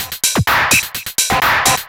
DS 127-BPM A8.wav